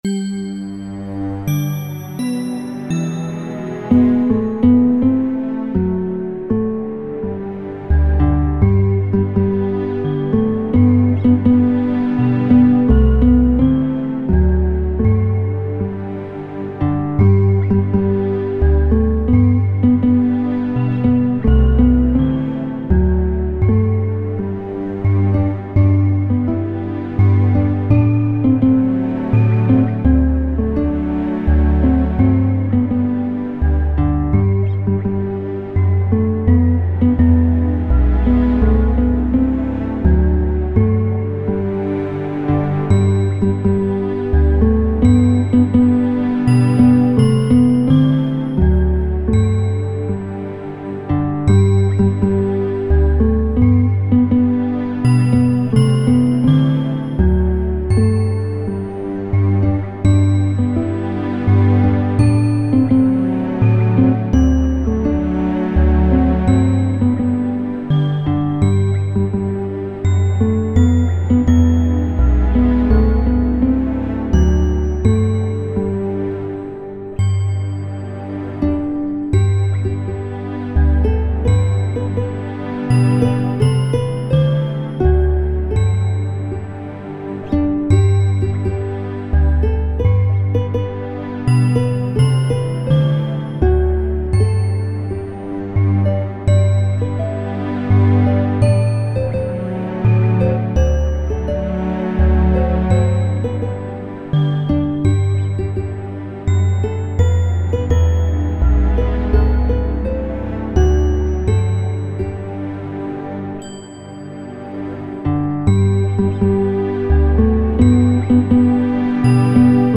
Christmas Karaoke
Canción de Navidad, España
karaoke